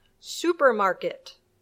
Pronunciación
supermarket.mp3